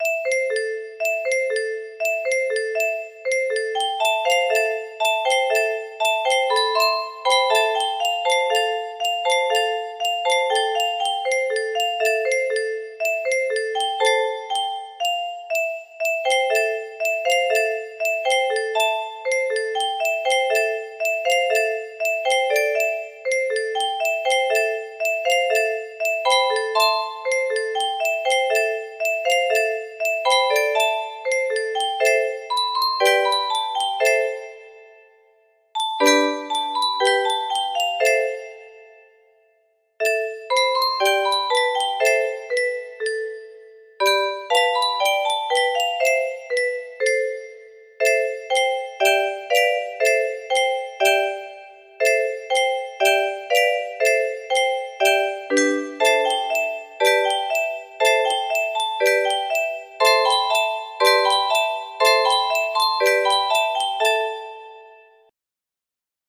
Exploring The Castle music box melody